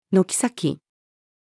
軒先-female.mp3